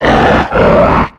Cri de Camérupt dans Pokémon X et Y.